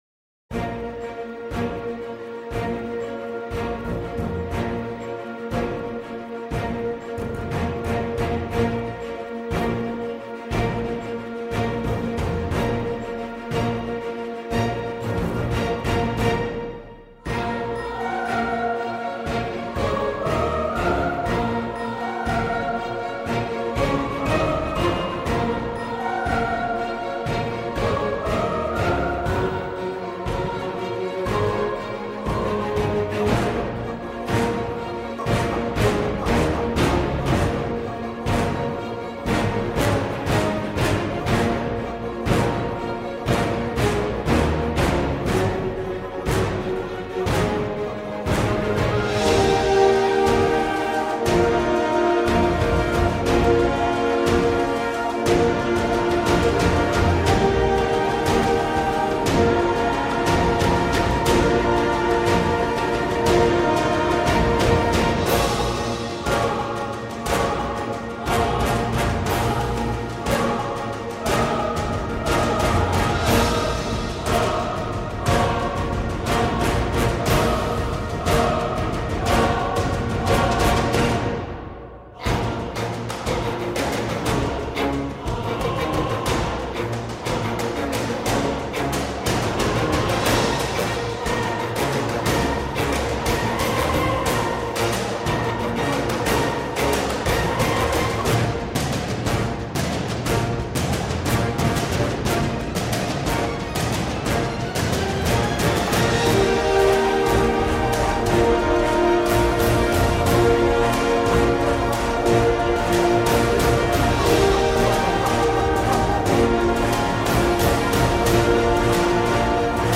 Orchestral fantasy track for platform worlds and RPG.